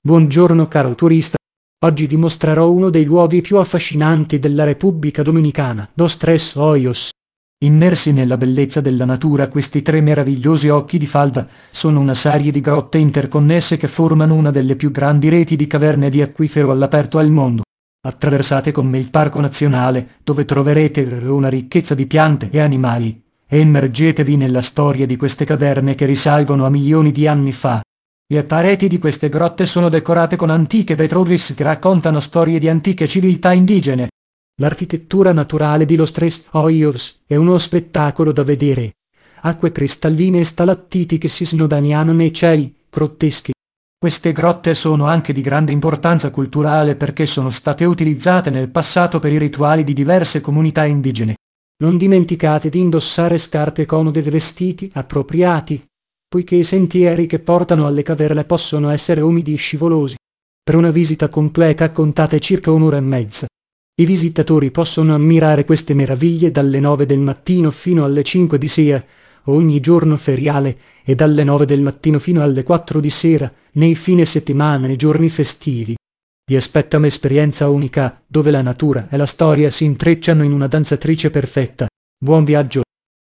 karibeo_api / tts / cache / e79aeab1bf4d9ce9f8c0dc09aa181181.wav